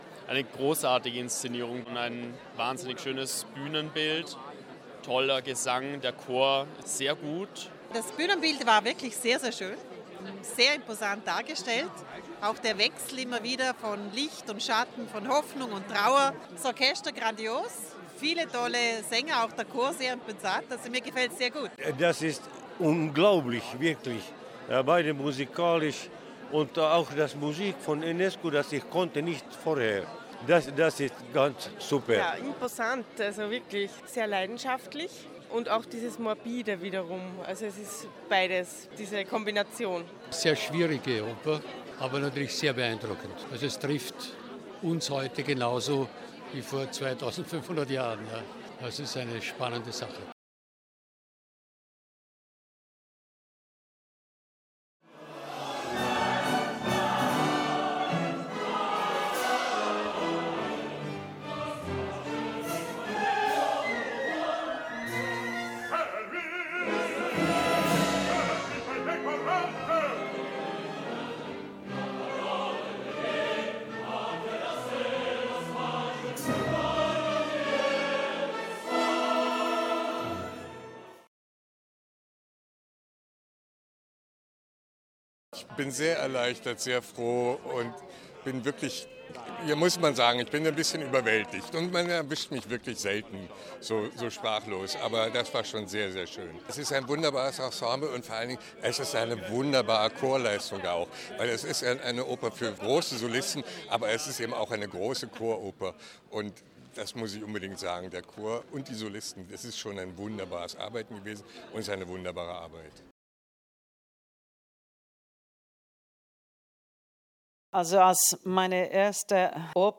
OT | Premiere Œdipe | Feature